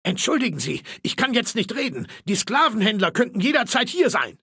Fallout 3: Audiodialoge